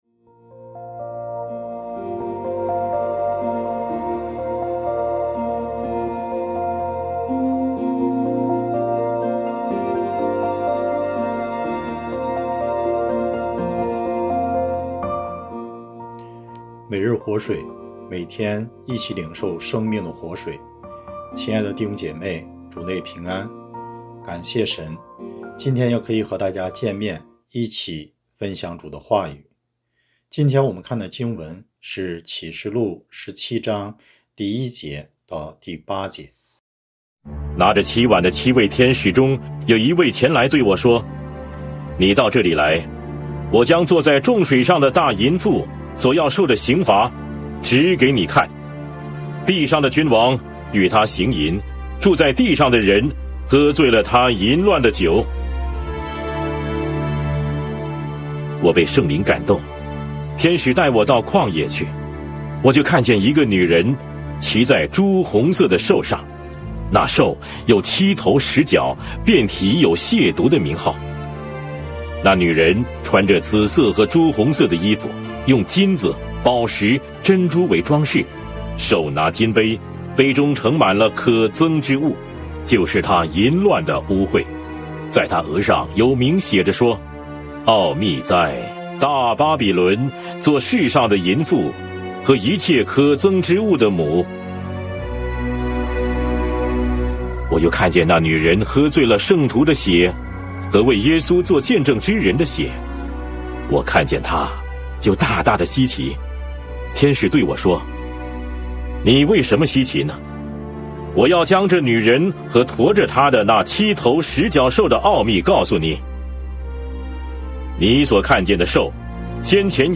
牧/者分享 每日活水，每天一起领受生/命的活水。